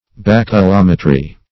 Search Result for " baculometry" : The Collaborative International Dictionary of English v.0.48: Baculometry \Bac`u*lom"e*try\, n. [L. baculum staff + -metry.] Measurement of distance or altitude by a staff or staffs.